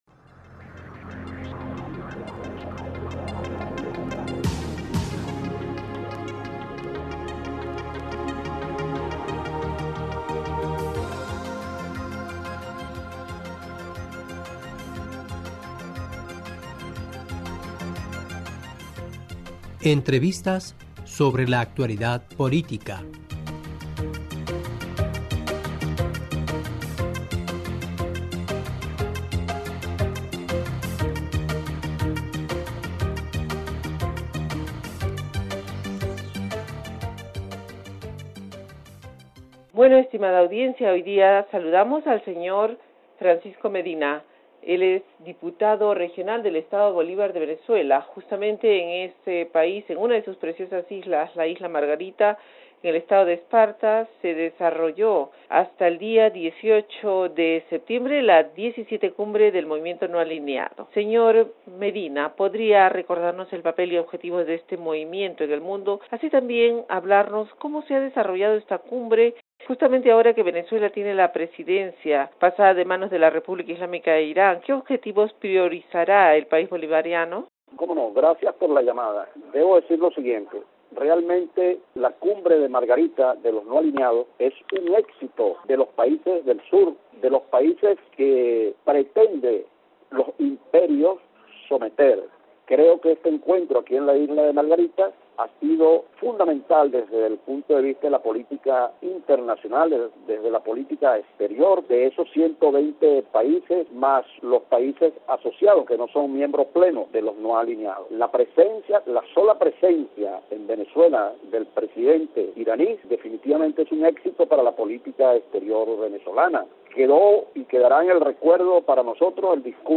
FM: Como no. Gracias por la llamada.